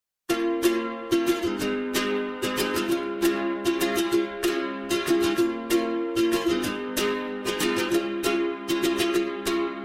• Качество: 128, Stereo
Красивый звук Гитары